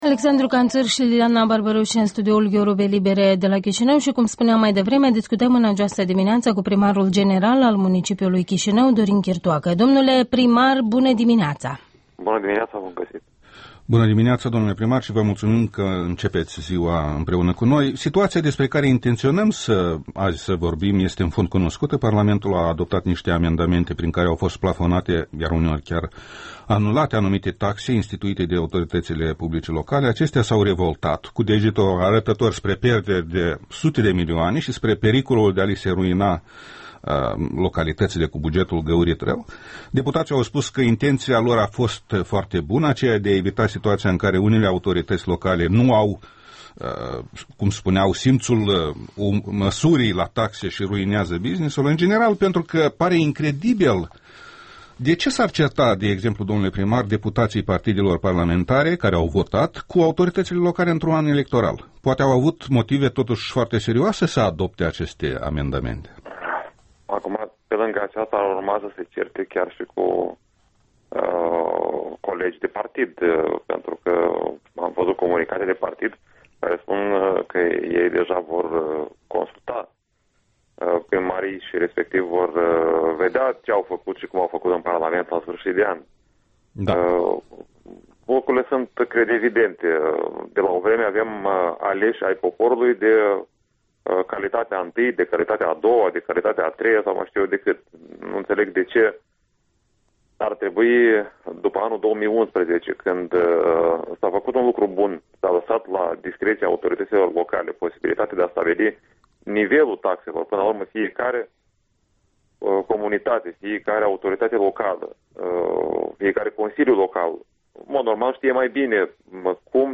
Interviul dimineții: cu Dorin Chirtoacă, primarul general al Capitalei